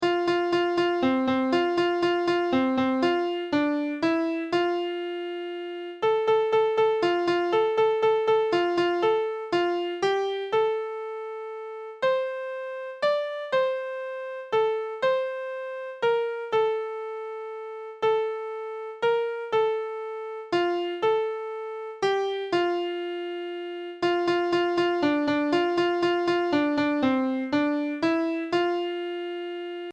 2 part round